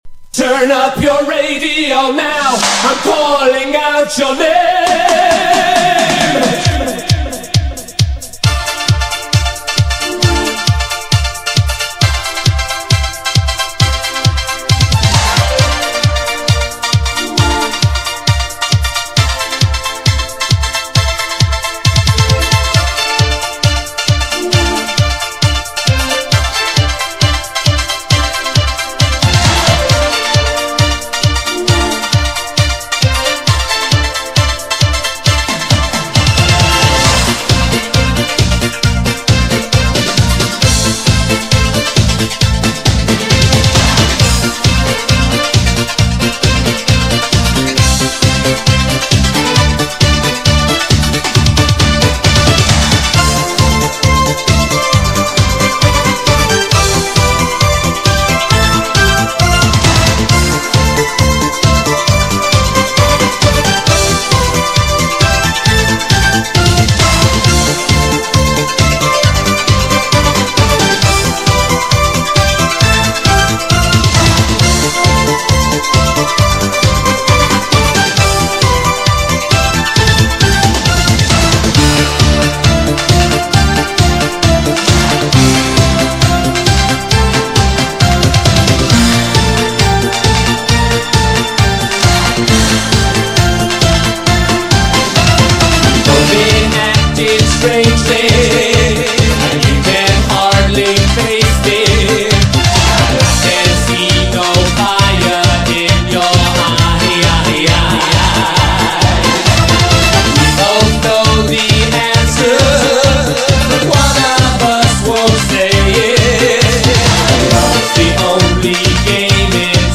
イケイケで上がりっぱなしです。
GENRE Dance Classic
BPM 131〜135BPM